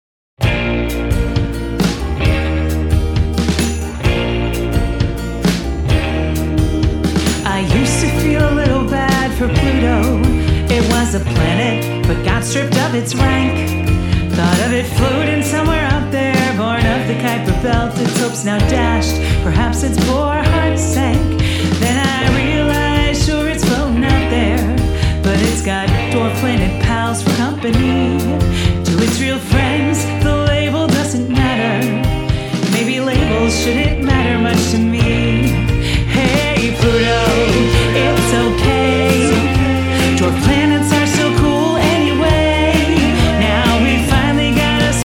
uses a 60’s rock flair